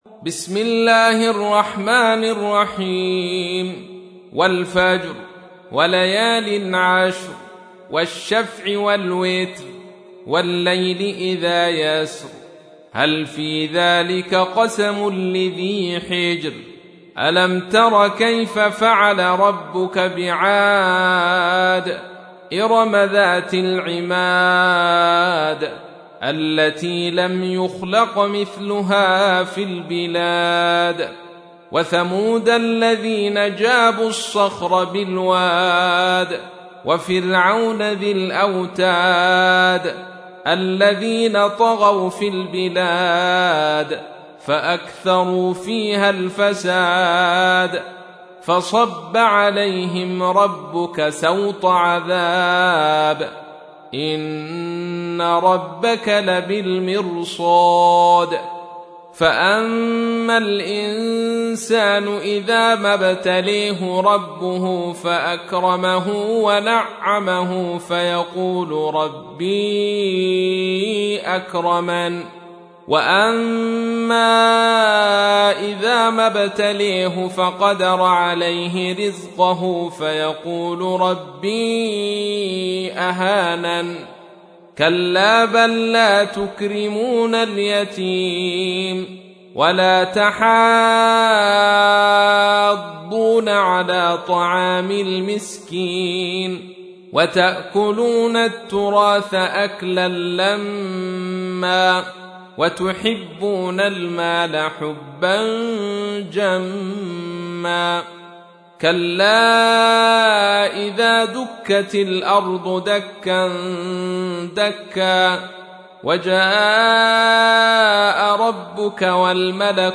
تحميل : 89. سورة الفجر / القارئ عبد الرشيد صوفي / القرآن الكريم / موقع يا حسين